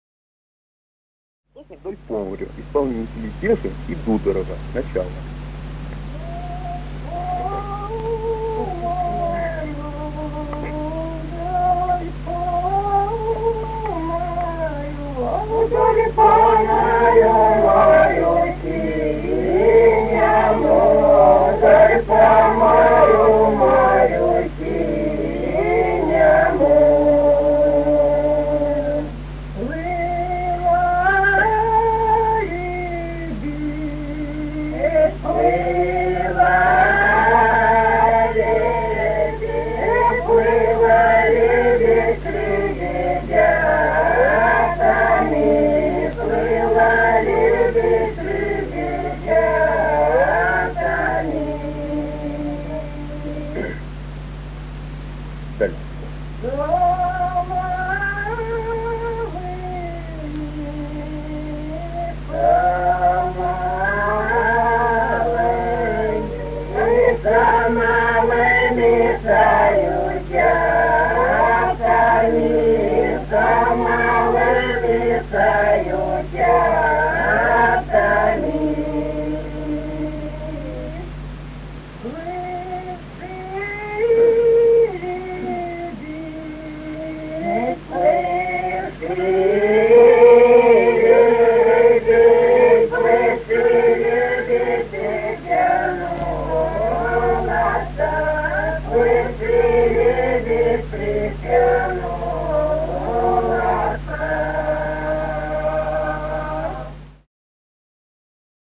Русские народные песни Владимирской области 34. Вдоль по морю, морю синему (хороводная) с. Михали Суздальского района Владимирской области.